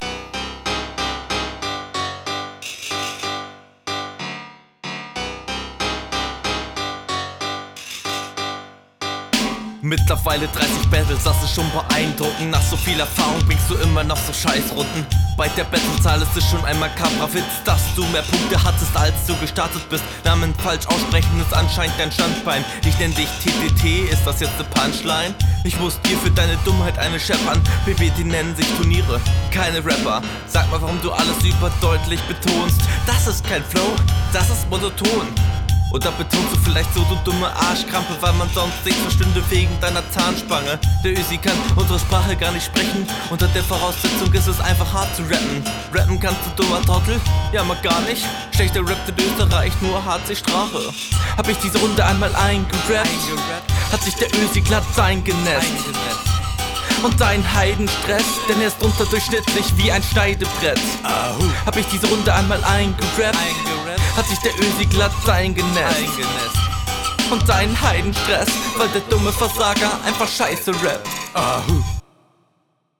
Ungewöhnlicher Beat.
Flow und Aussprache leider bisschen unroutiniert.